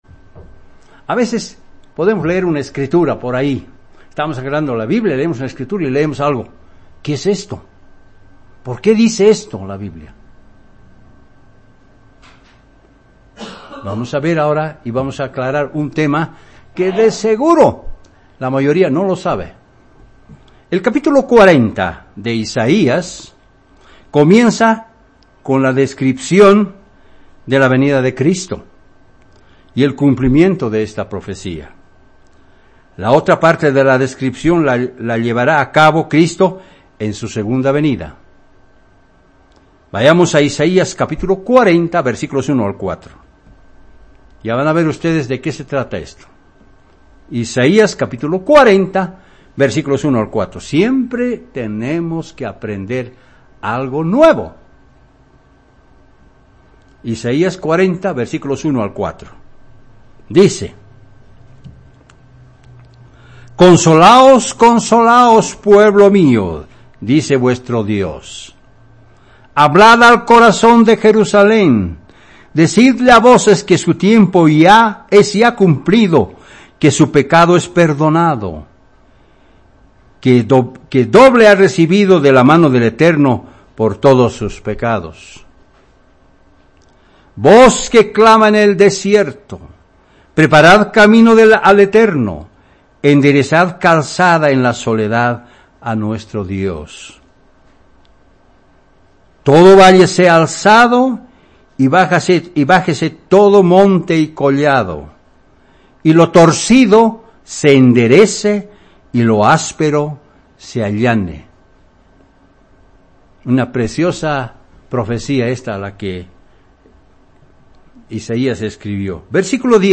Mensaje entregado el 7 de abril de 2018.